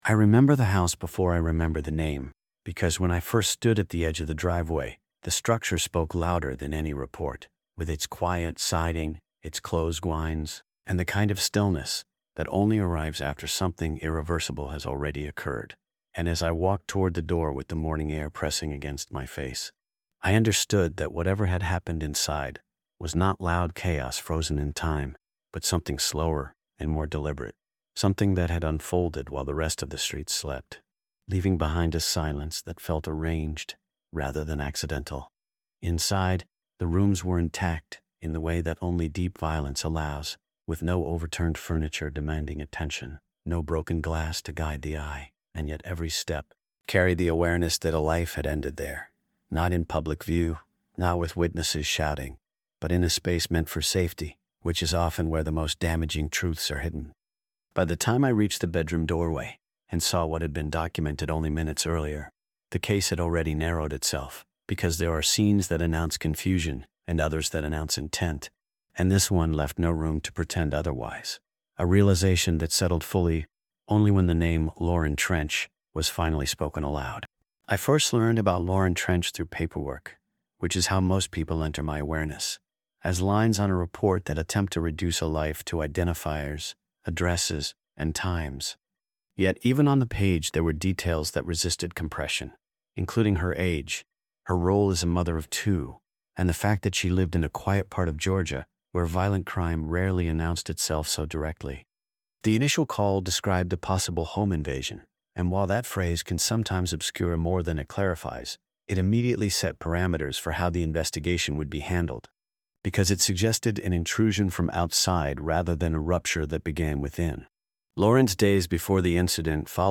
Told through the voice of a first-person detective narrator, this true-crime audiobook reconstructs the case using only verified evidence, official records, and confirmed timelines.